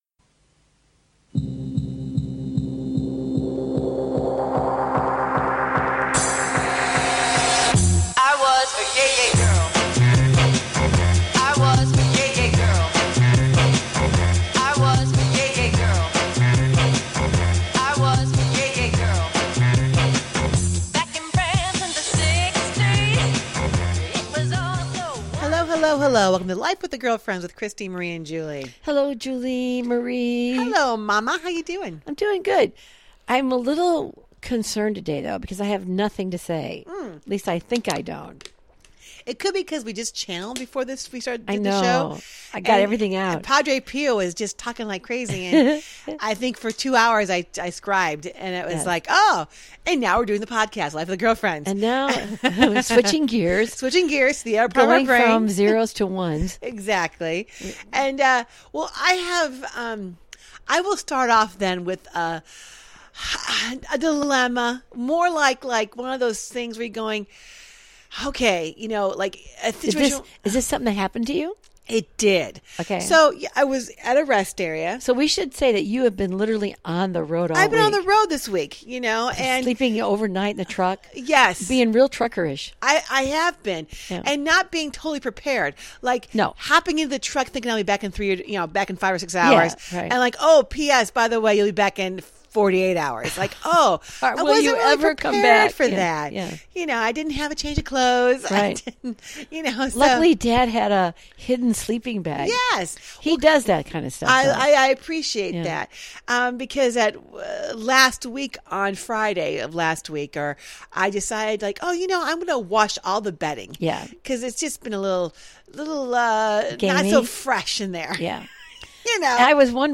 This mother/daughter coaching duo shares their everyday thoughts on relationships, family, hot topics and current events, and anything that tickles their fancy with warmth, wit, and wisdom.
And join the girlfriends up close and personal for some daily chat that’s humorous, wholesome, and heartfelt.